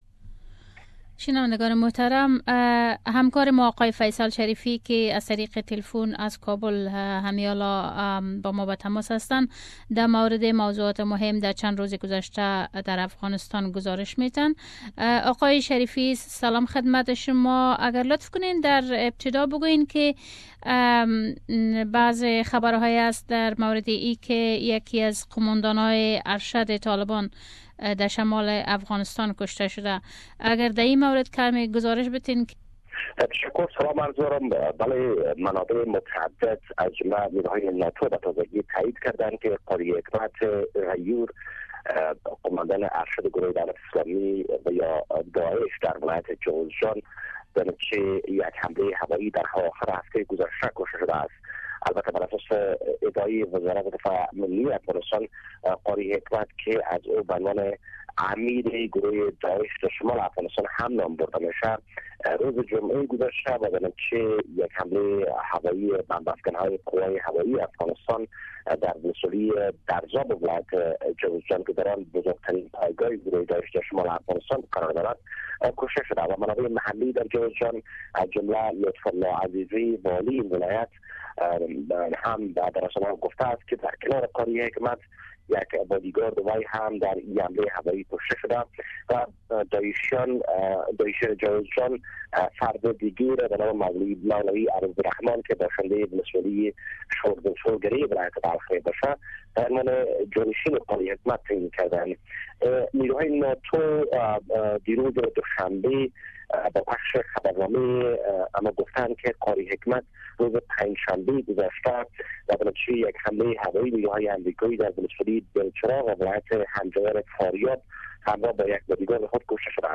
Report from Kabul